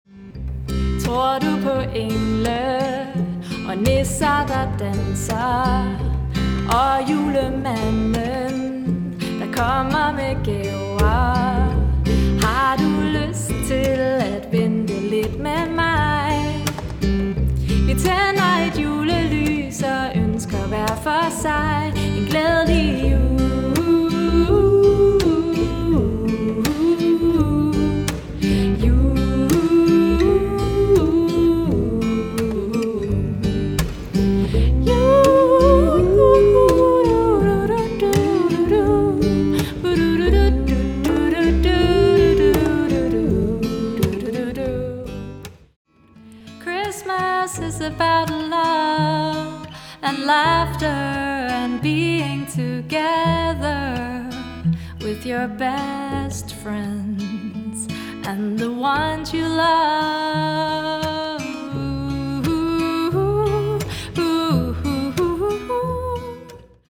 • Vintersange
Solo